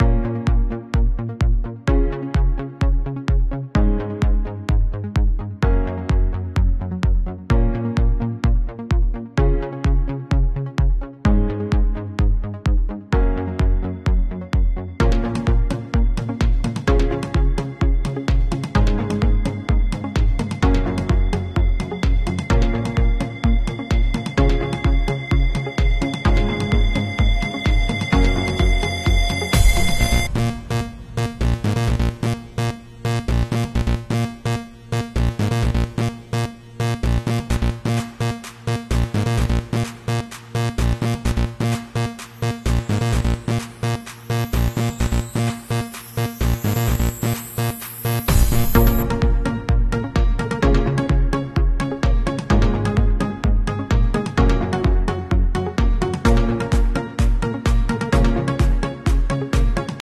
ein Server von Fujitsu sound effects free download